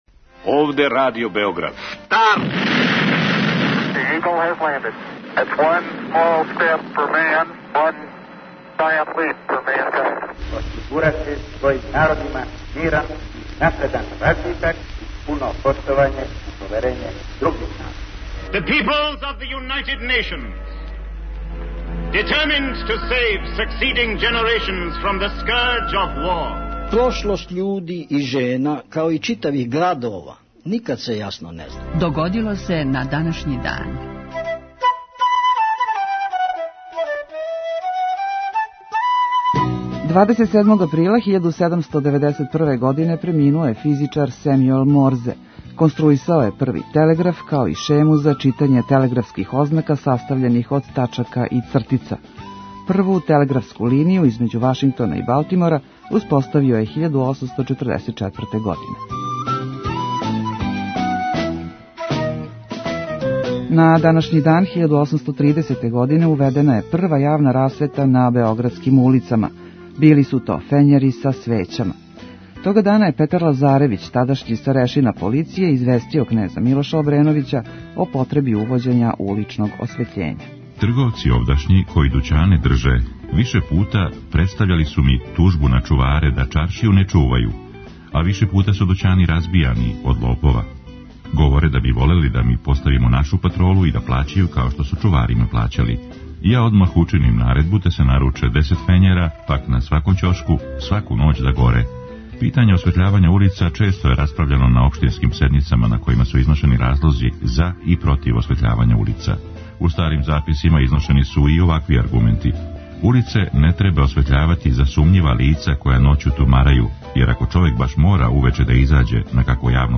Емисија Догодило се на данашњи дан, једна од најстаријих емисија Радио Београда свакодневни је подсетник на људе и догађаје из наше и светске историје. У петотоминутном прегледу, враћамо се у прошлост и слушамо гласове људи из других епоха.